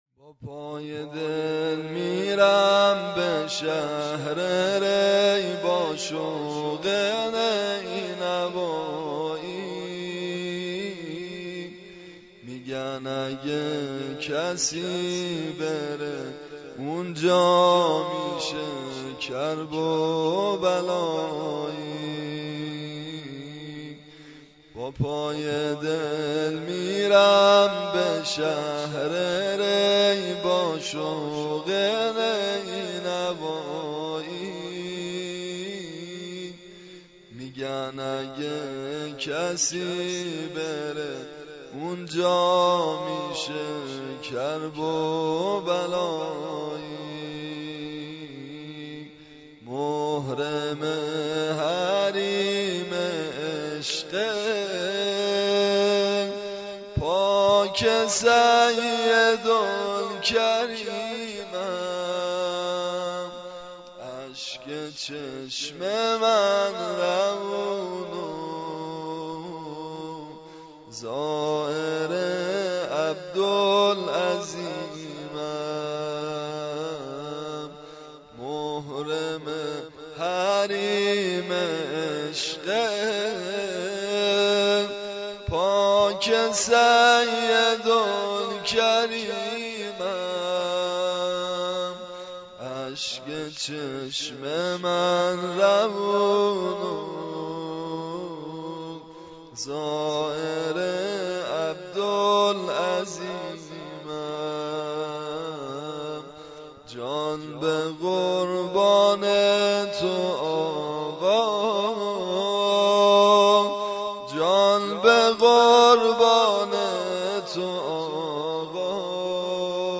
1 آخرین مطالب موسیقی مداحی